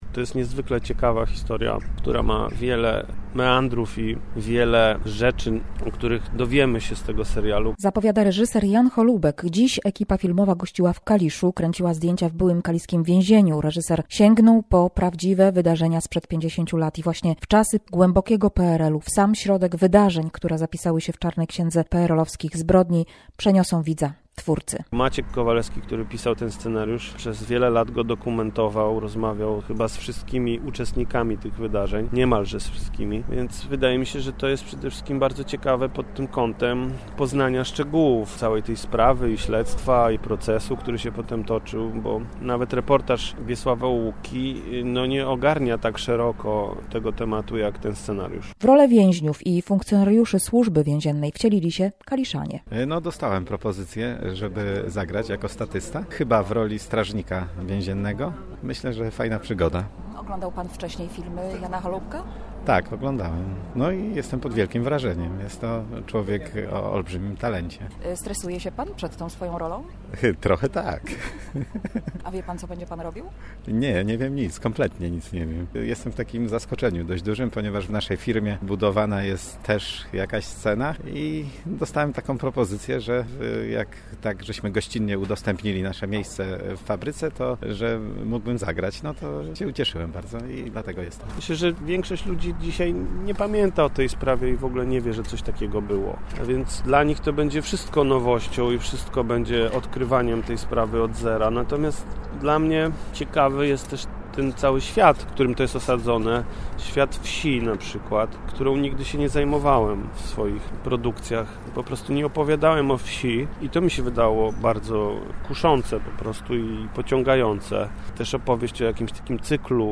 - Serial powstaje na podstawie scenariusza Macieja Kowalewskiego, który rozmawiał ze świadkami zdarzeń - zdradził w wywiadzie dla Radia Poznań reżyser Jan Holoubek.